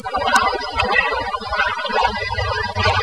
EVP 6: I'm dead